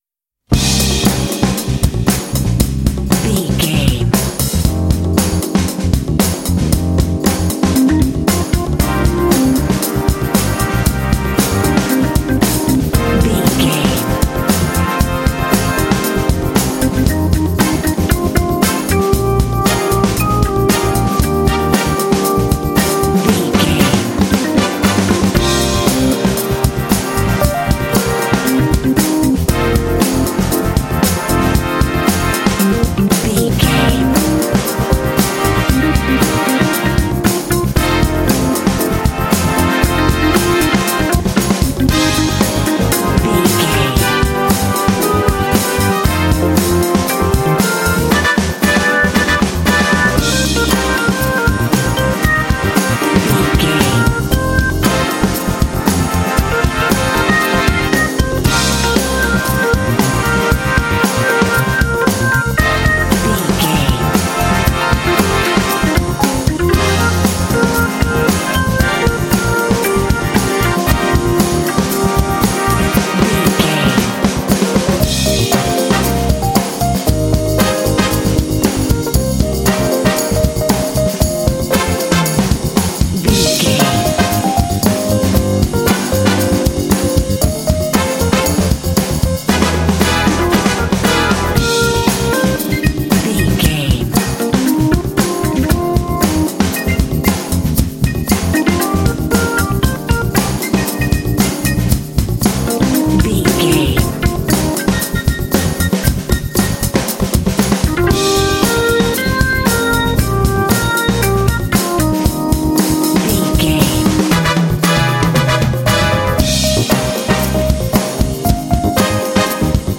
Uplifting
Ionian/Major
E♭
funky
groovy
confident
piano
drums
percussion
bass guitar
electric piano
brass
electric organ
Funk
big band
jazz